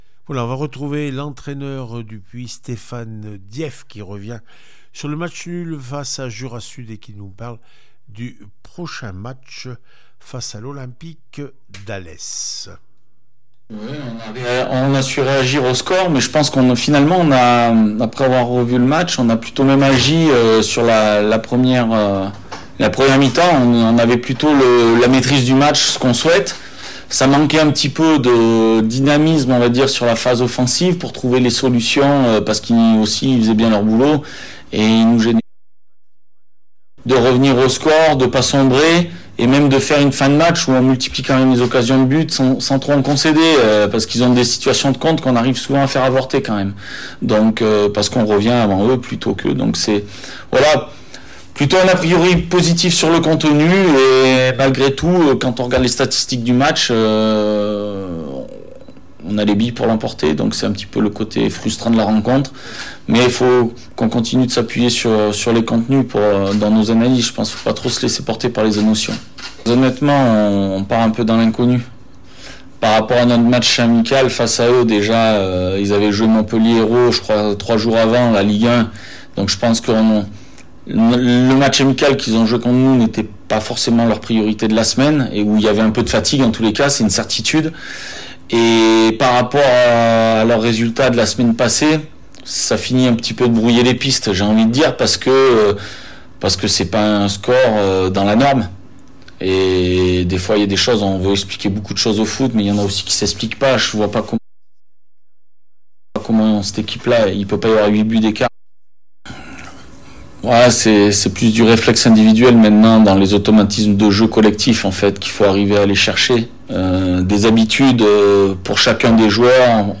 n2 foot olympique d’Ales en Cévennes-le puy foot 43 auvergne réactions avant match